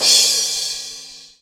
Percussion Hits/Drum Loop Hits
Crash (Rollin' Ride).wav